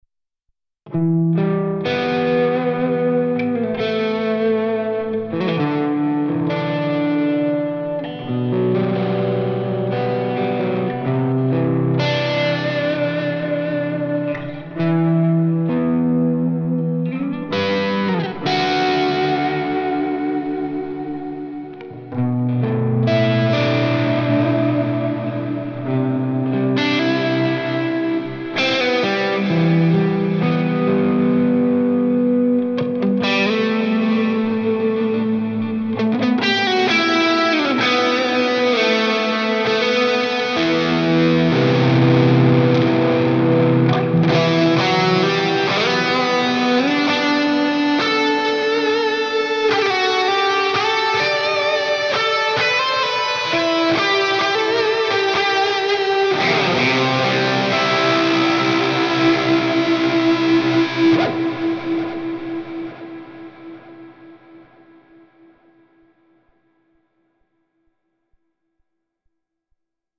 This Amp Clone rig pack is made from a Marshall JVM 410H Preamp.
IR USED: MARSHALL 1960A V30 SM57+ E906 POS 1
RAW AUDIO CLIPS ONLY, NO POST-PROCESSING EFFECTS
Hi-Gain